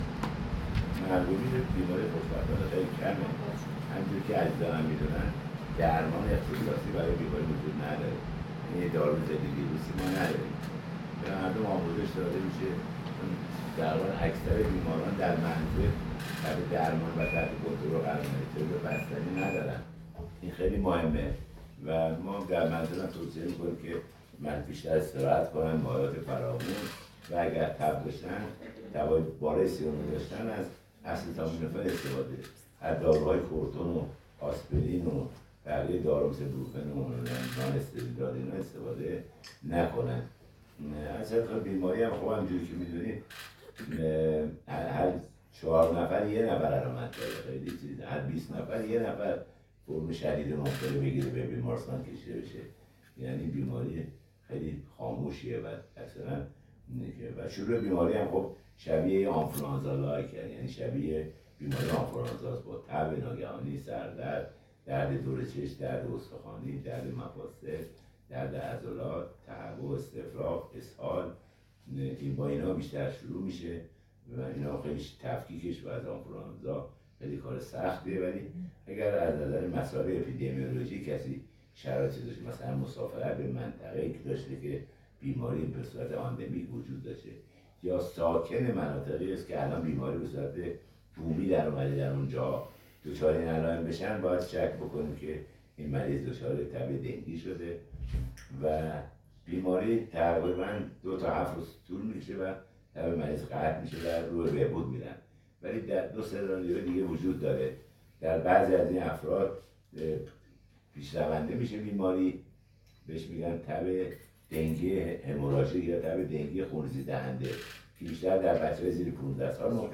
AudioCutter_مصاحبه تب دنگی(4).mp3